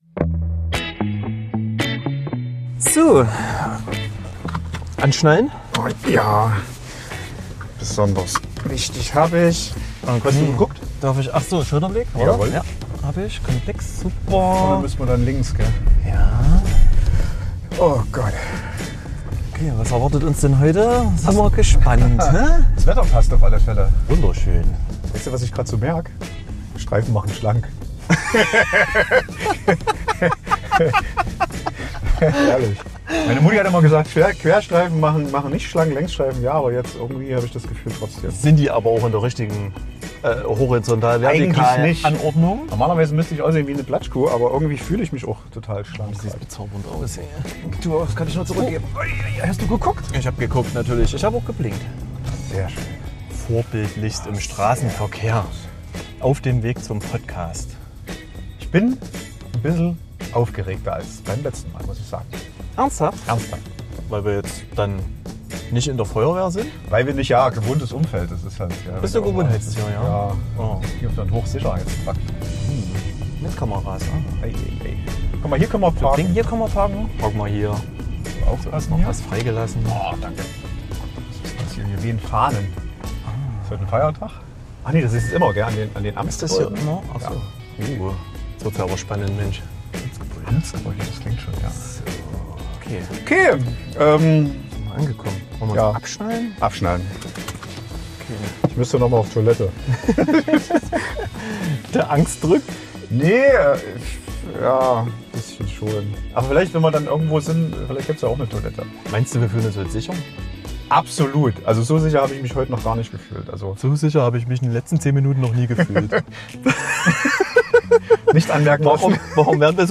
Zu Gast sind zwei junge, bezaubernde Polizistinnen aus unterschiedlichen Bereichen der Landespolizeiinspektion Gera.
Aufgenommen haben wir diese Folge an einem ganz besonderen Ort.